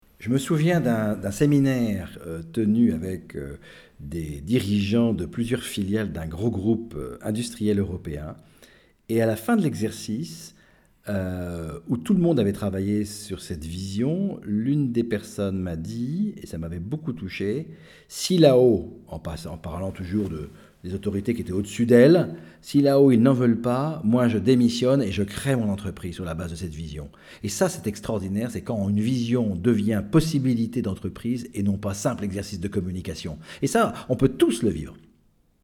Dans un court commentaire audio destiné à ceux qui pensent trop vite qu’ils ne seront jamais, ni visionnaires, ni entrepreneurs de leurs vies